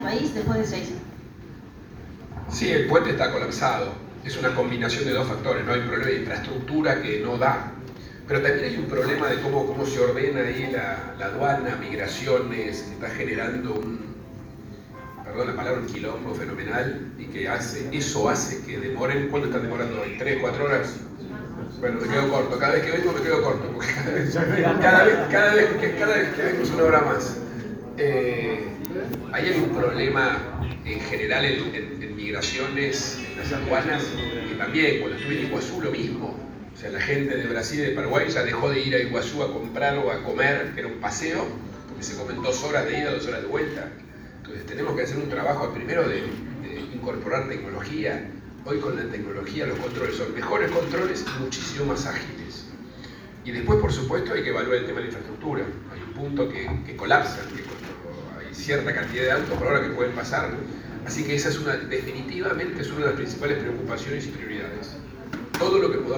De esa forma, el jefe de gobierno porteño calificó el caos cada vez mayor que se genera en el viaducto internacional y que prometió solucionar con tecnología y un mejor funcionamiento de esos servicios en caso de llegar a la Presidencia, en una conferencia de prensa que hoy ofreció en el Hotel Urbano, en el marco de su visita de dos días a Posadas.
Audio: Horacio Rodríguez Larreta (conferencia Hotel Urbano Bagu/ 28-7-23)